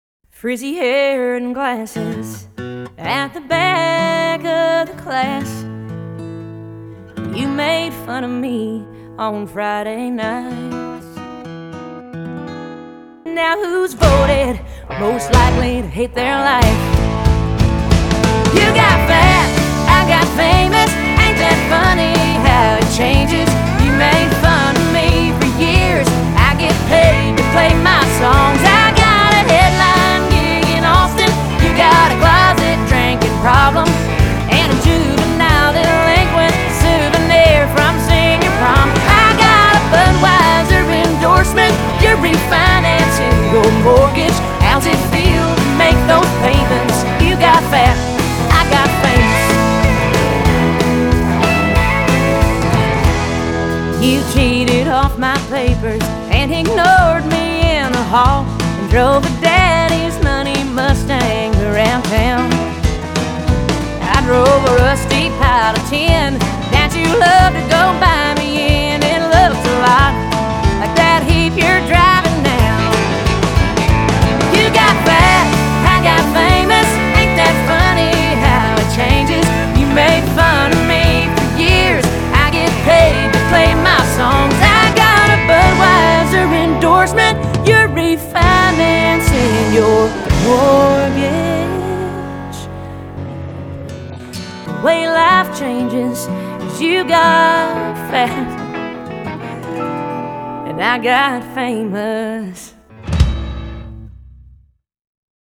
February 2025 June 15, 2025 Two Step